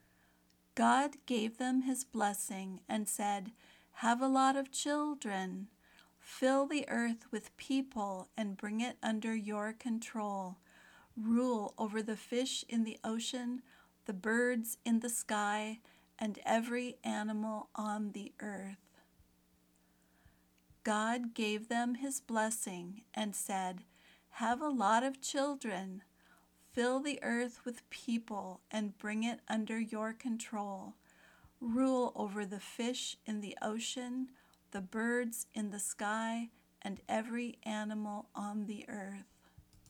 Speaking Exercise
If you are learning American English, imitate her pronunciation the best you can.